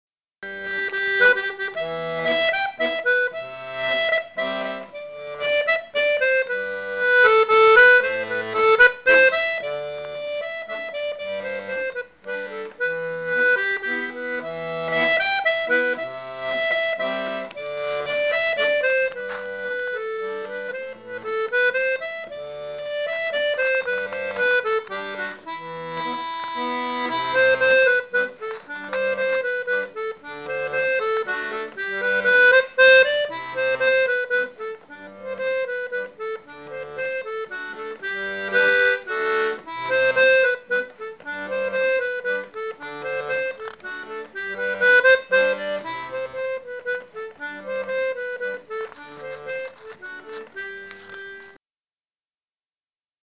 l'atelier d'accordéon diatonique
la mélodie